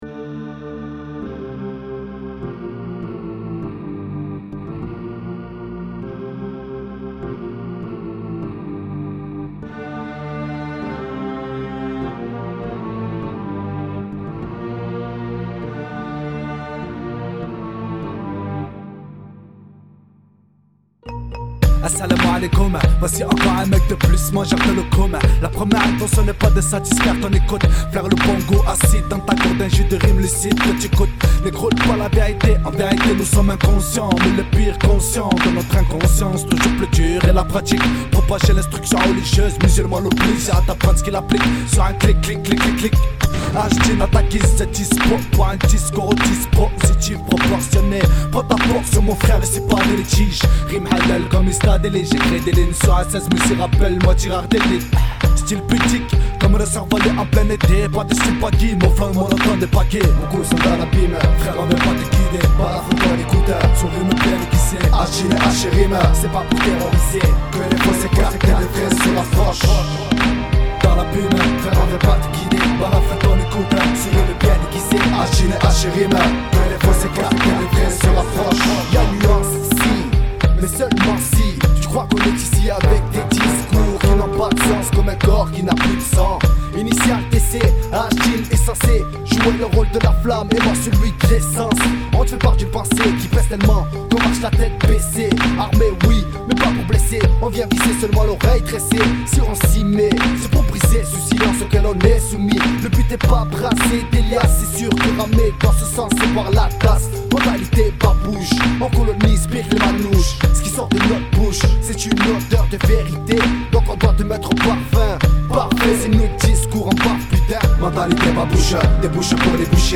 Groupe de rap